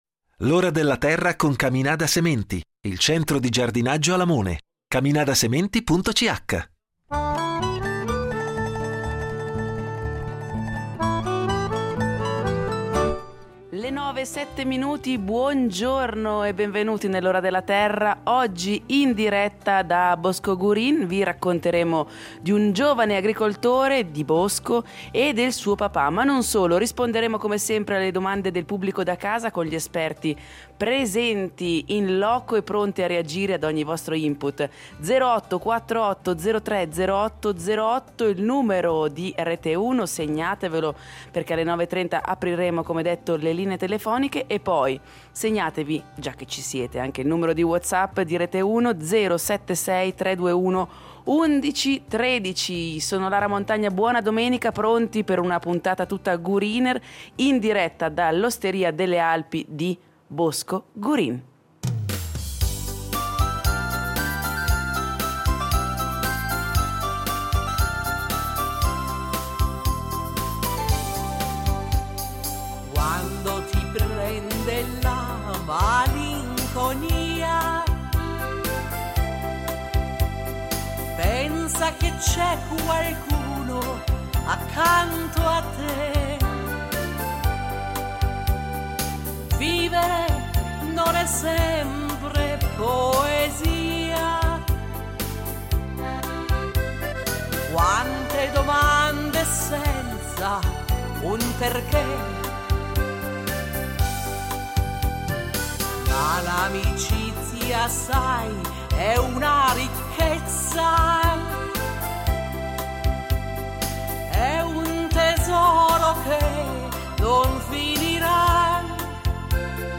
In diretta dall’Osteria delle Alpi
Non mancheranno i tre esperti del programma, che risponderanno alle domande del pubblico da casa.